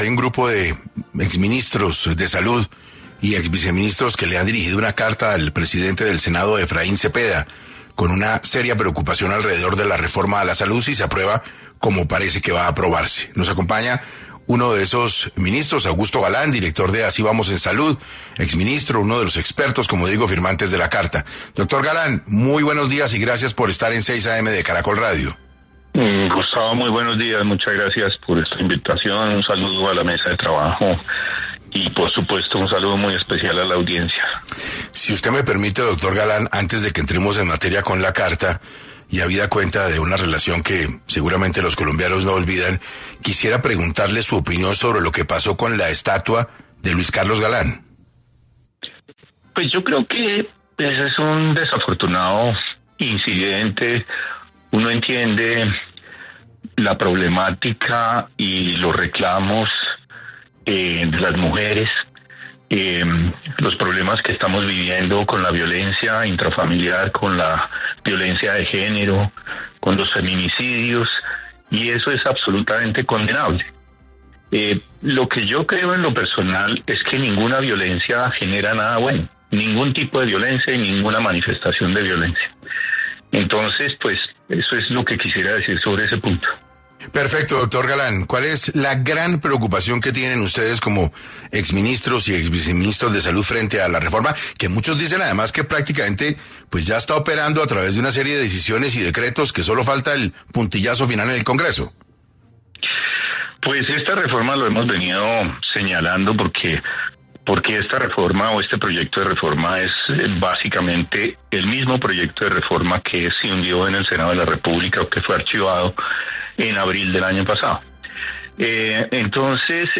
Augusto Galán, director de ‘Así Vamos en Salud’, estuvo en 6AM para hablar de las mayores preocupaciones sobre la reforma de salud del gobierno Petro.